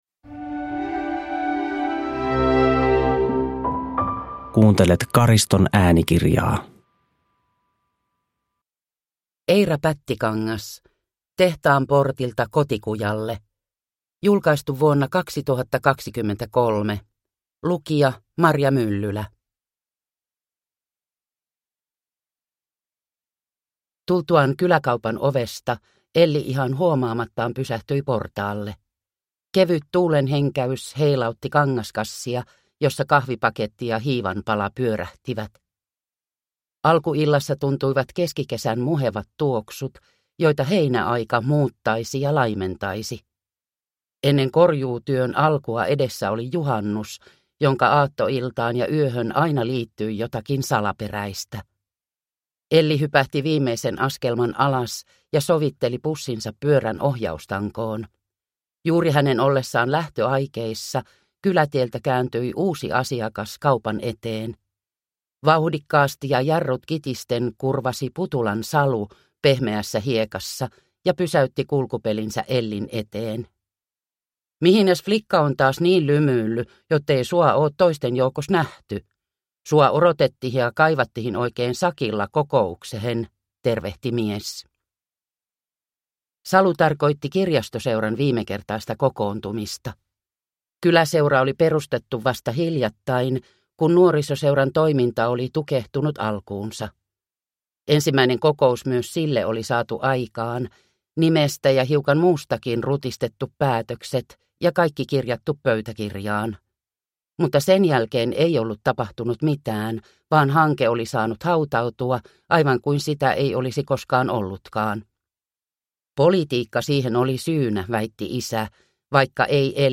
Tehtaan portilta kotikujalle – Ljudbok – Laddas ner